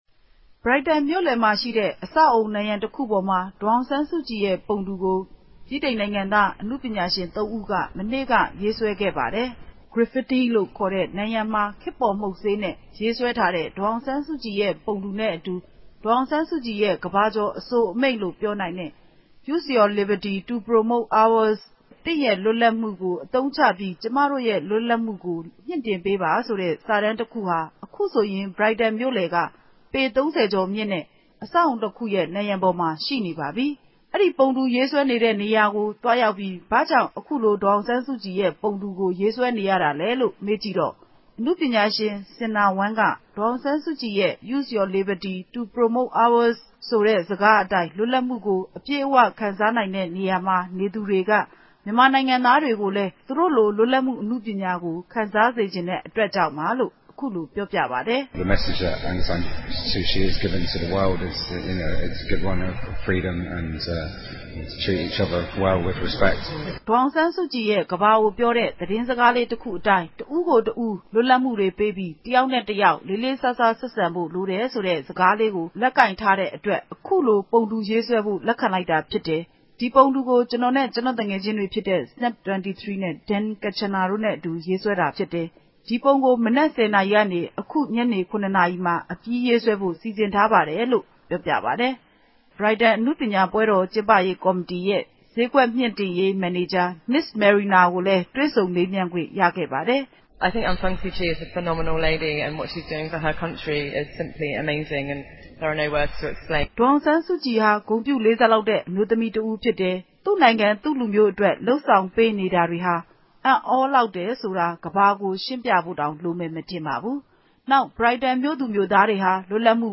တွေ့ဆုံမေးမြန်းချက်